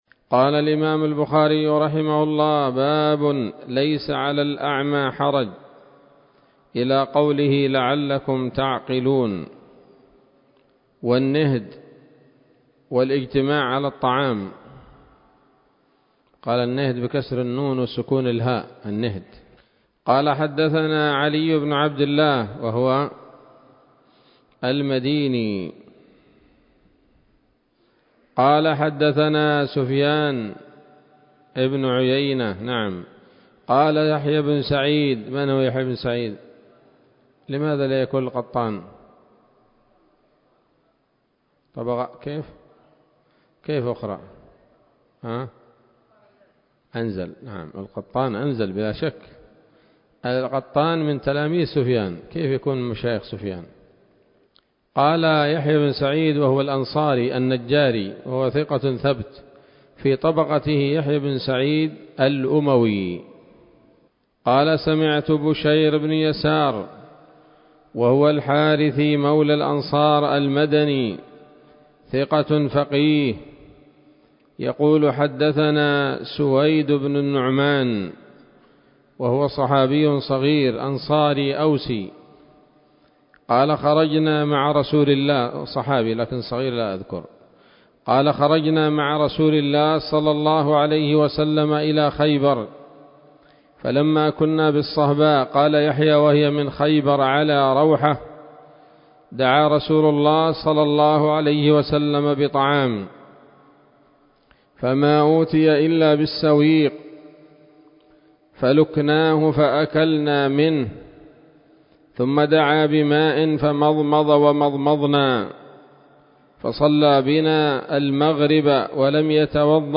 الدرس الخامس من كتاب الأطعمة من صحيح الإمام البخاري